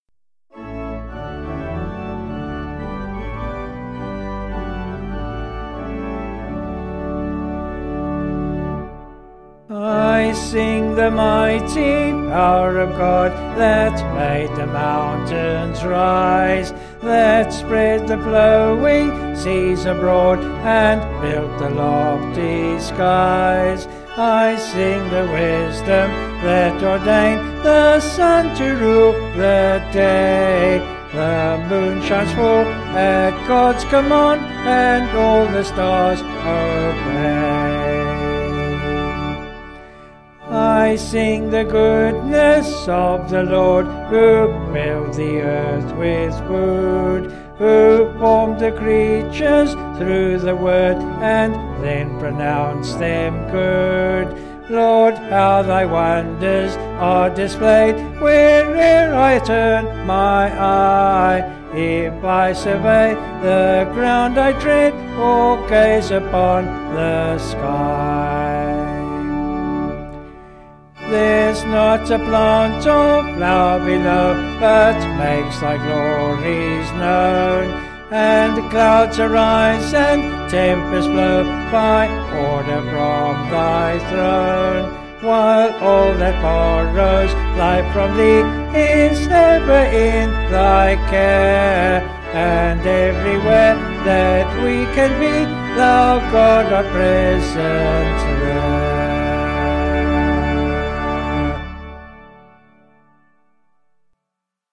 Vocals and Organ   263.9kb Sung Lyrics